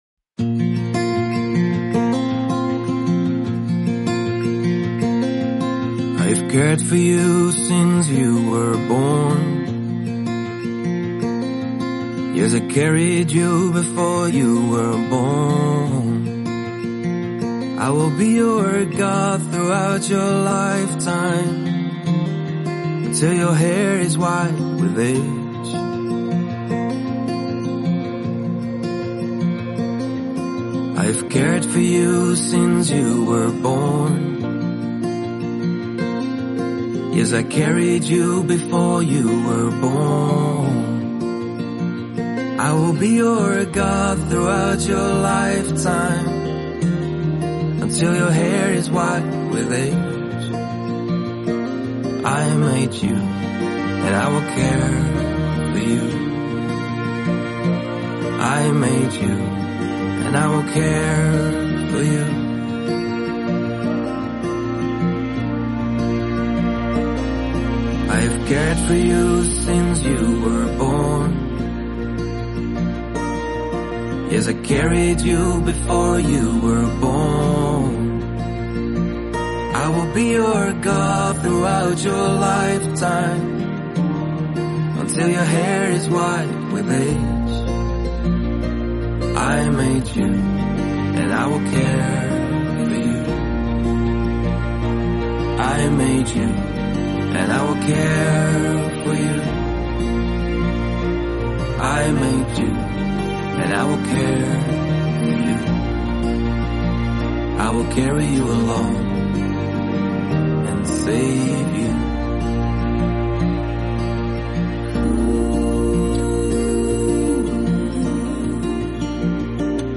I then later recorded and produced them in my studio.